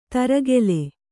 ♪ taragele